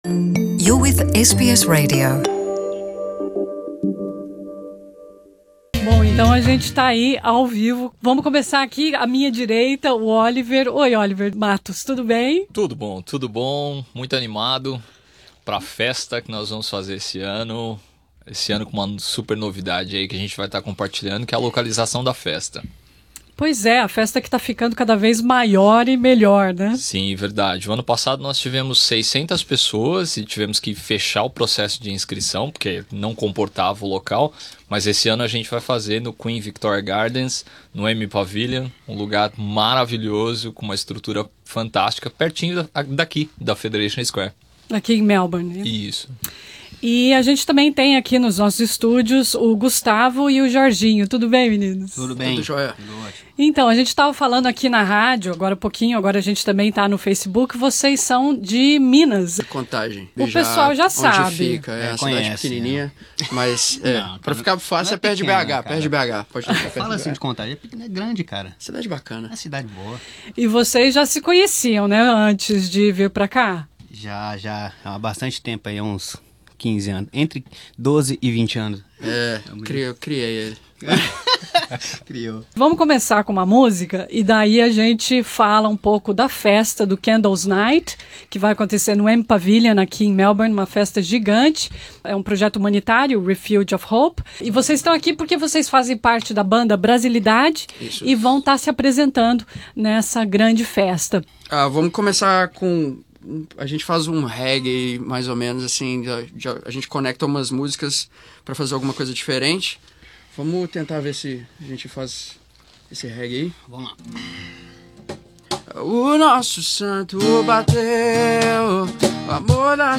Ouça a divertida entrevista dada pelo trio nos nossos estúdios.